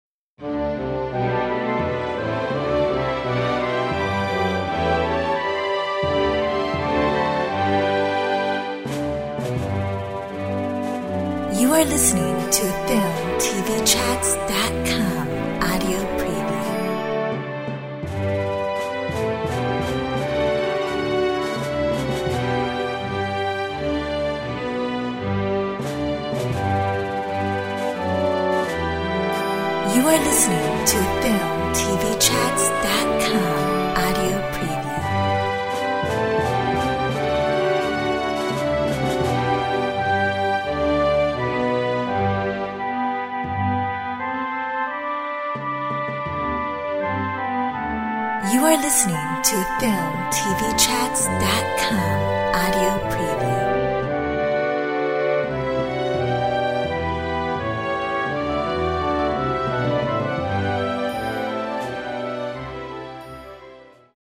Corporate , POP